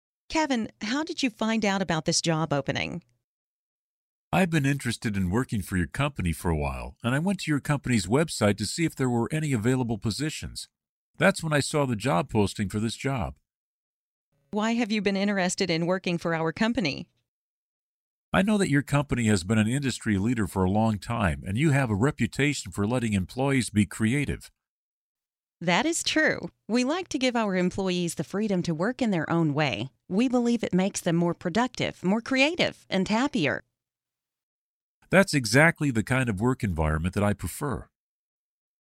Learn different ways to answer the interview question 'How did you find out about this job opening?', listen to an example conversation, and study example sentences